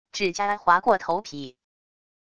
指甲划过头皮wav音频